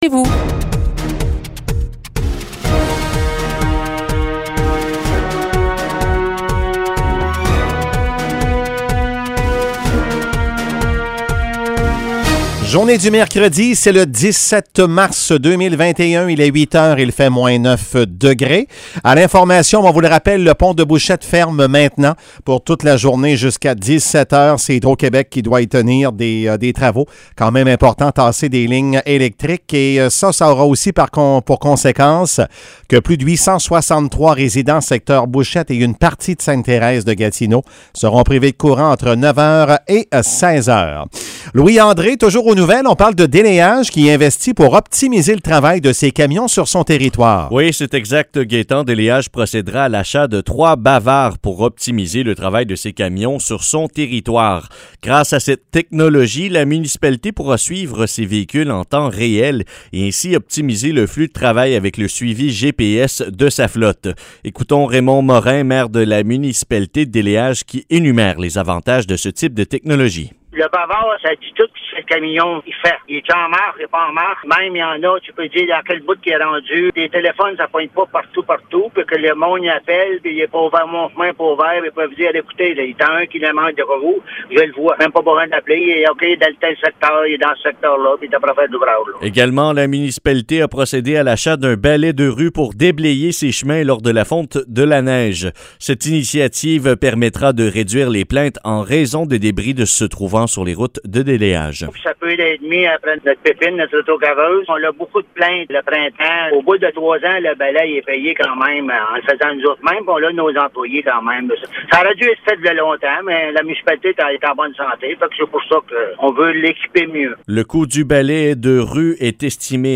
Nouvelles locales - 17 mars 2021 - 8 h